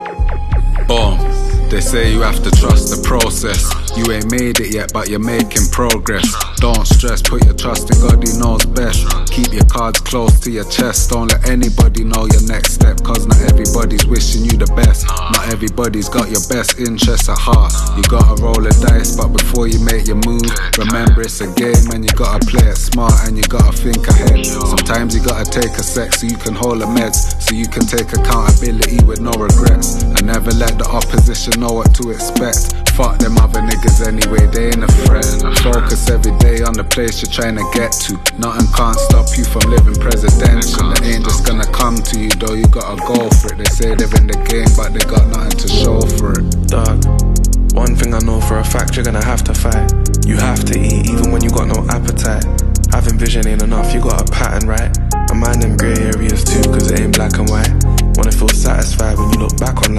Motivational Speech